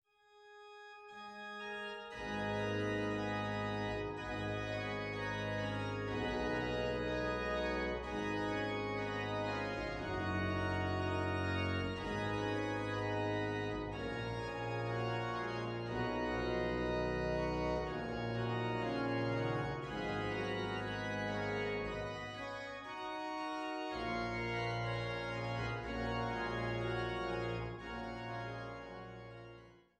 Hildebrandt-Orgel in Langhennersdorf